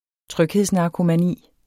Udtale [ ˈtʁœgheðs- ]